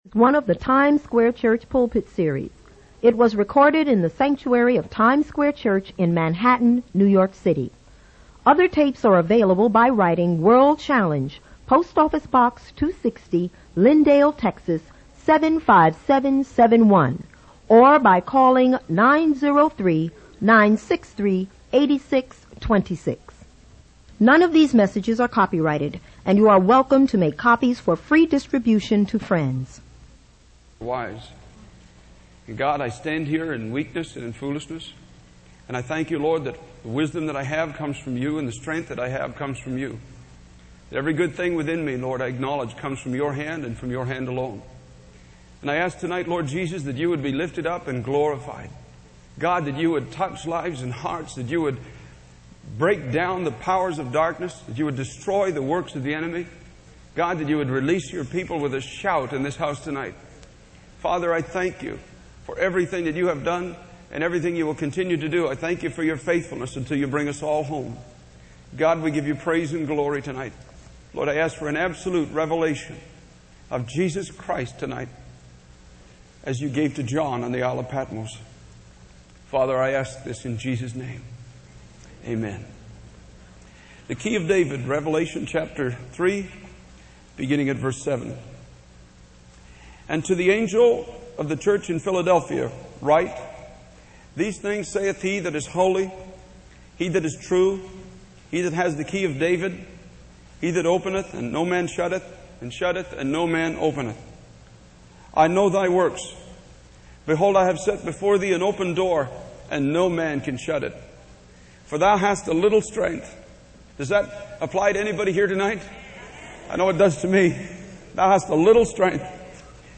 In this sermon, the speaker addresses the concerns of those who question the intensity and radicalness of one's relationship with God.